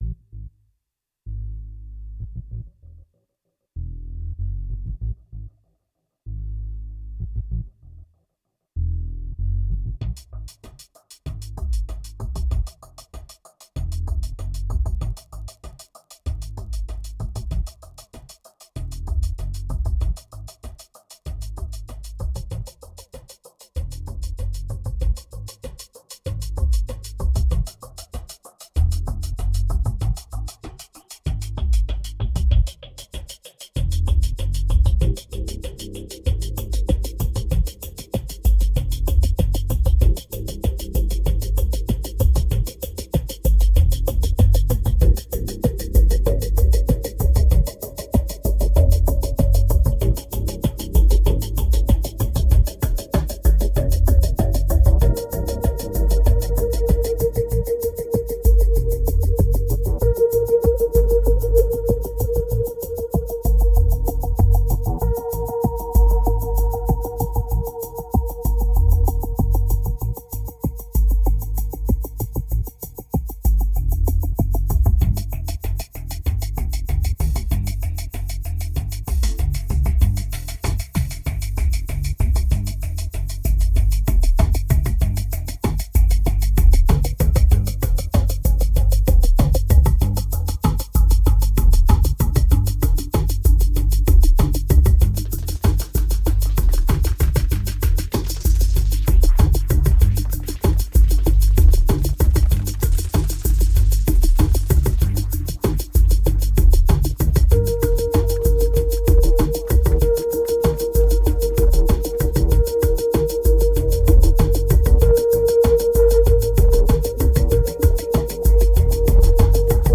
2225📈 - -1%🤔 - 96BPM🔊 - 2012-05-06📅 - -369🌟